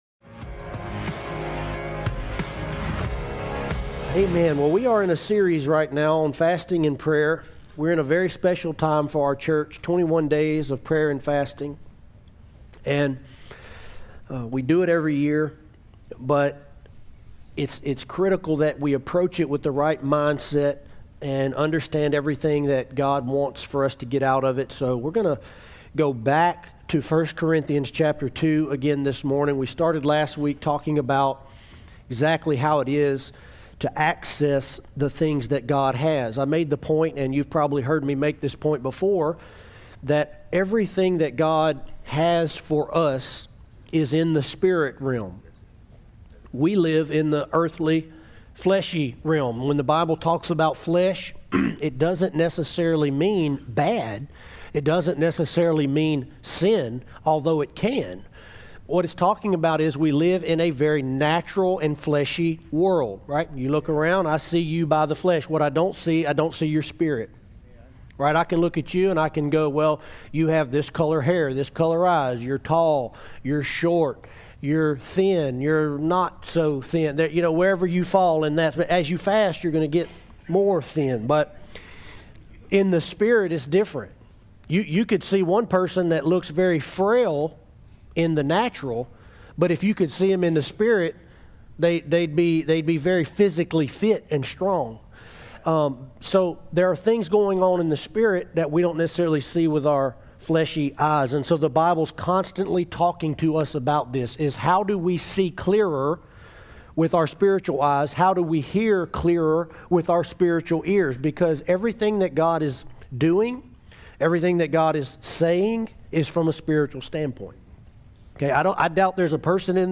Sermons from One Life Church Alexandria